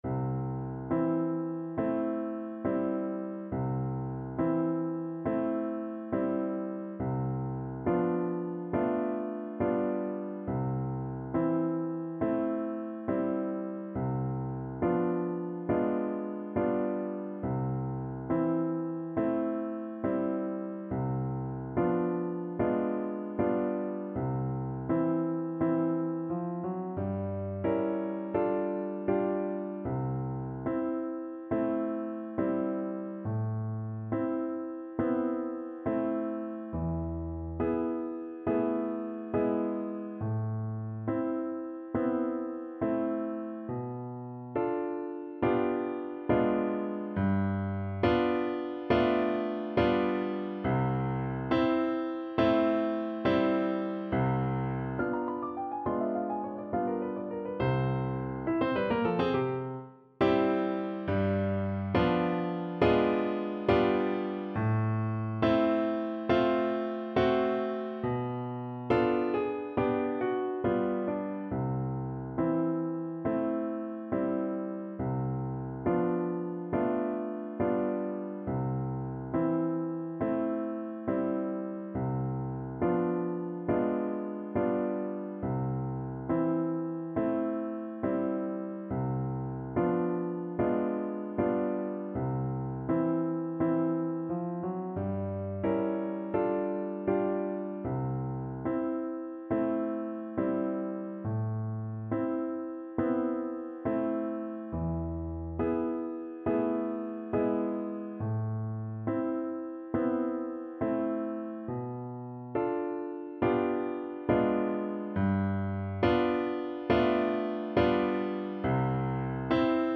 C major (Sounding Pitch) G major (French Horn in F) (View more C major Music for French Horn )
4/4 (View more 4/4 Music)
Andante non troppo con grazia =69
Classical (View more Classical French Horn Music)